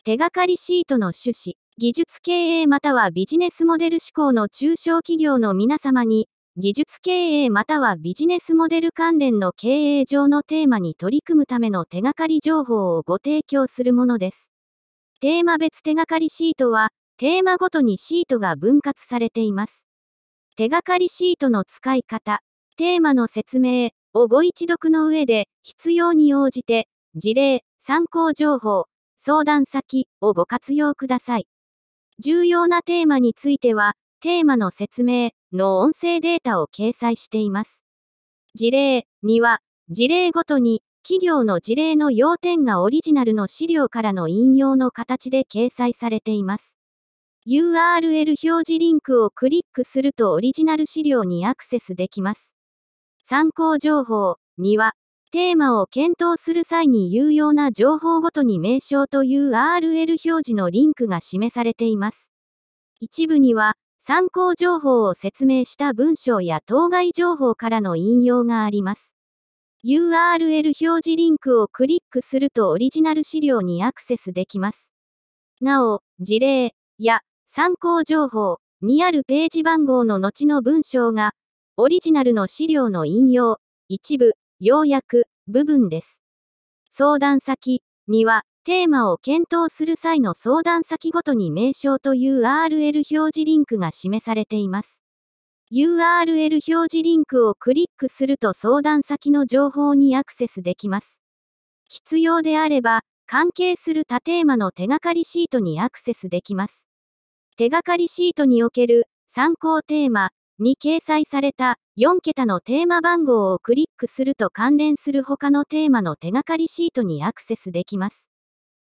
読み上げ音声データ＞＞